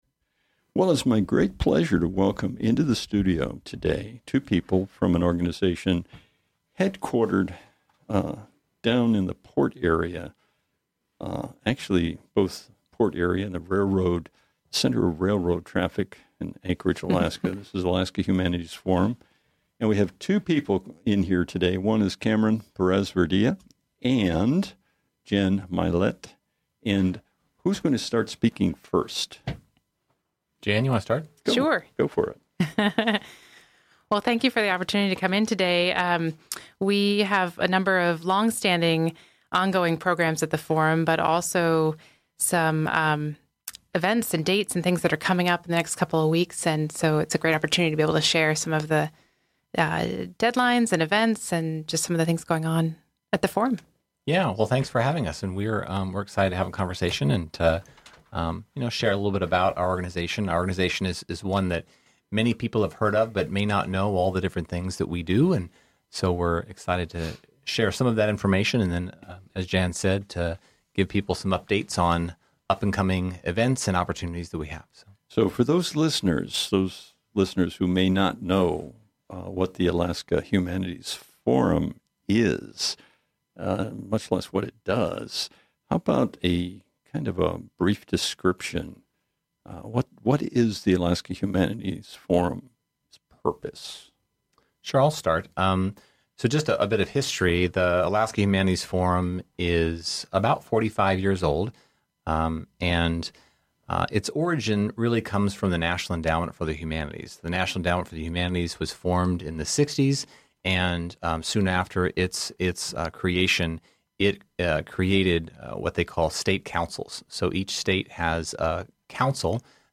Alaska Humanities Forum Update Interview 8.14.2017